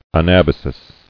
[a·nab·a·sis]